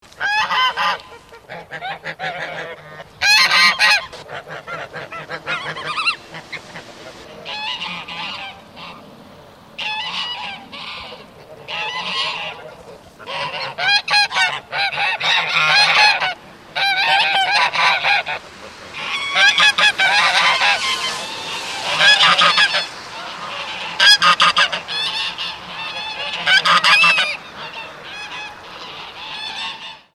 Ganso Toulouse (Anser anser toulouse)